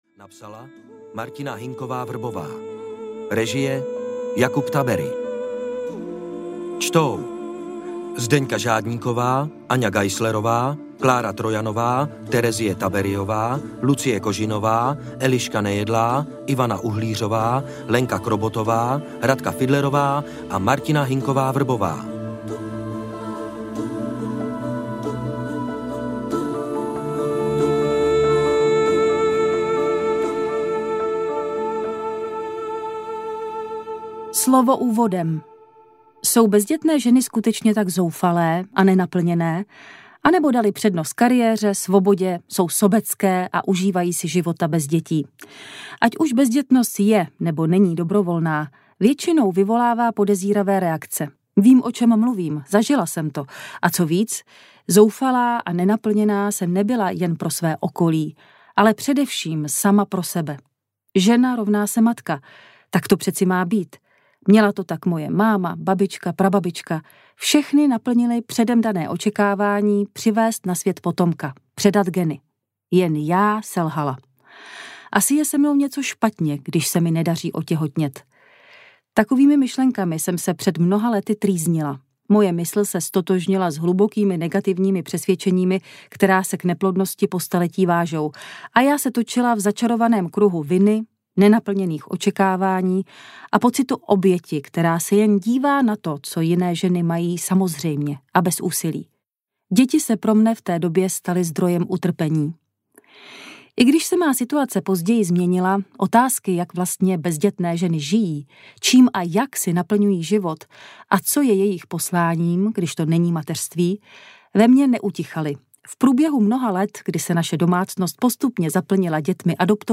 Žiju bez dětí audiokniha
Ukázka z knihy
ziju-bez-deti-audiokniha